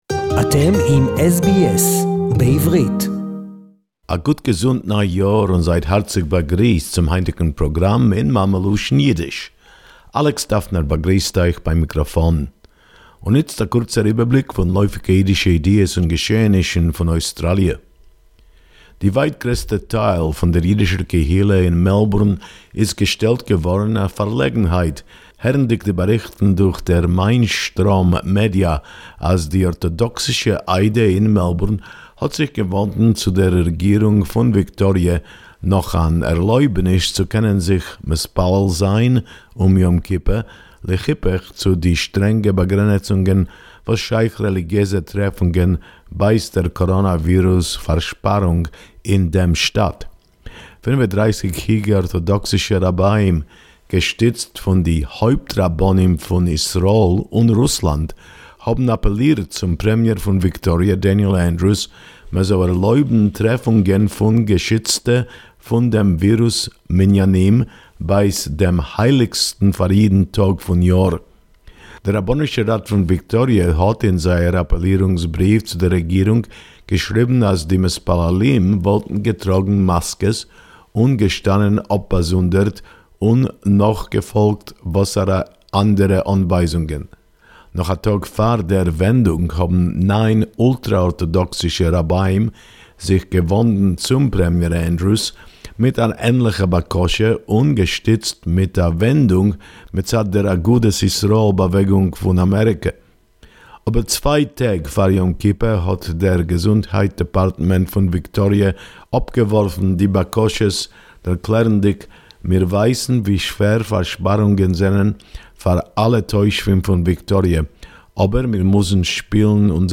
A unique and comprehensive weekly report in Yiddish